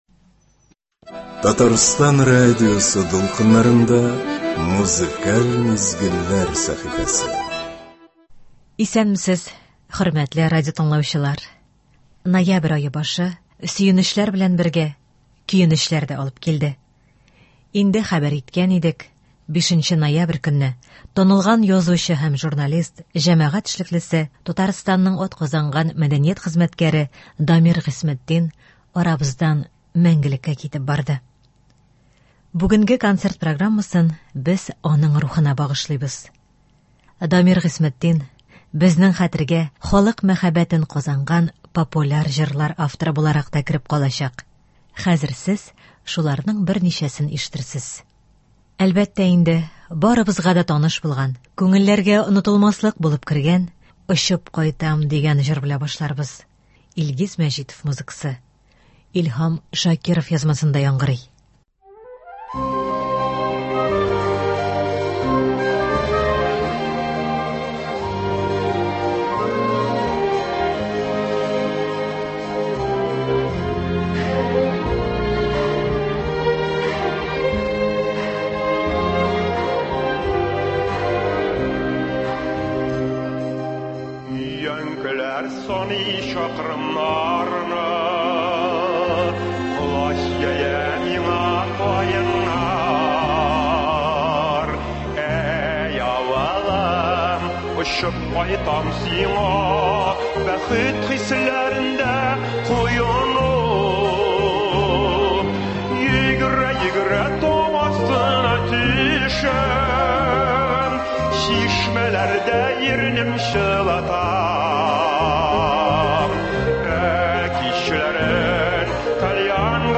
популяр җырлар